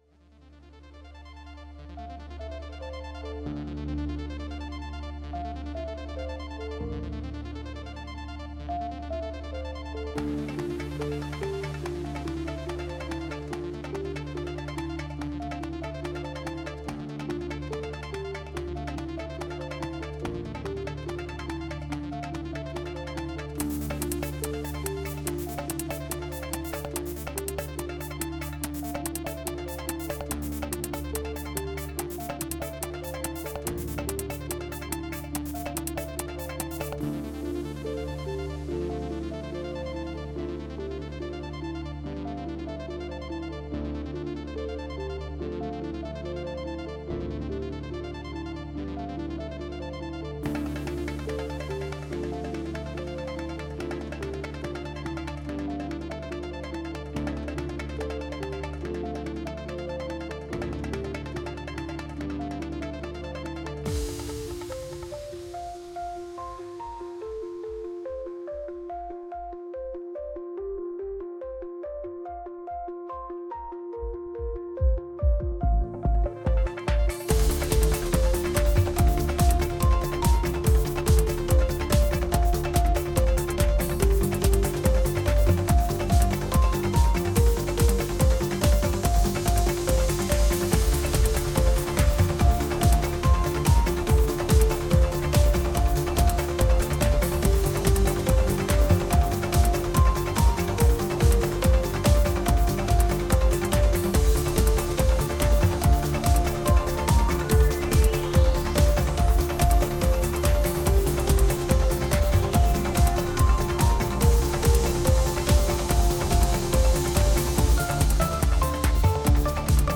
Mix of the tracks